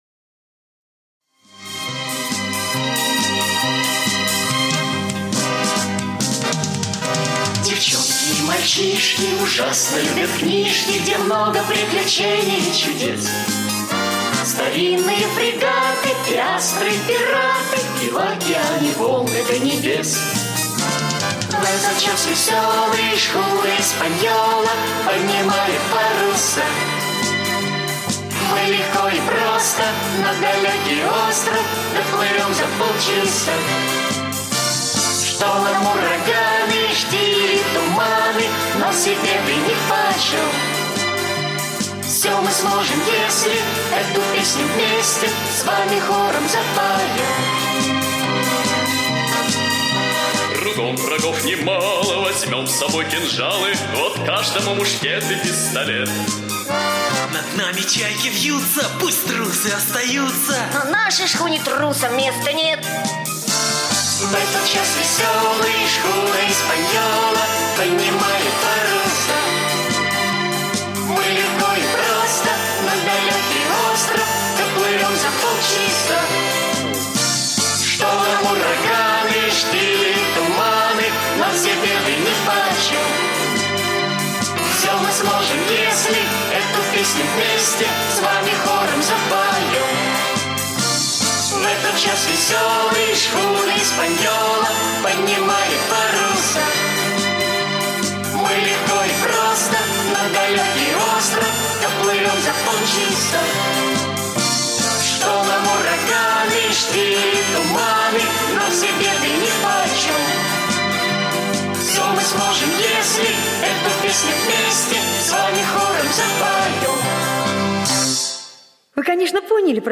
Слушайте Сокровища капитана Флинта - аудиосказка-мультфильм. Сказка про знаменитый остров сокровищ и пиратов.